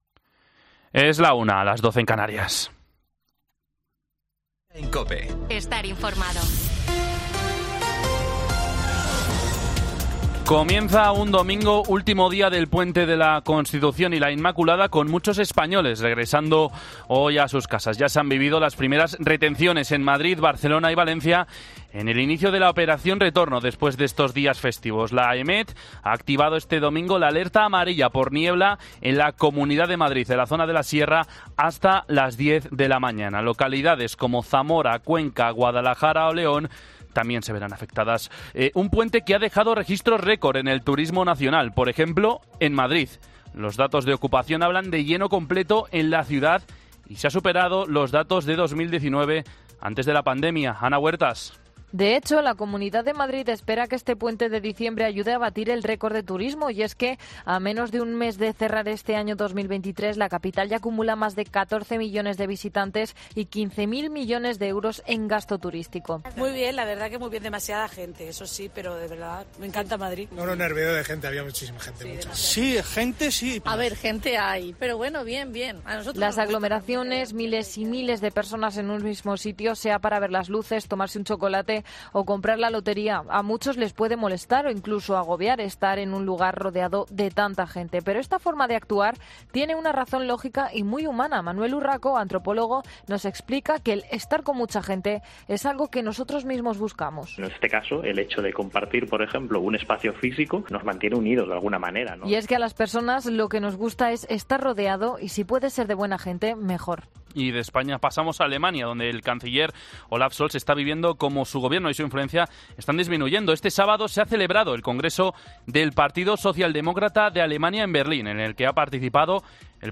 Boletín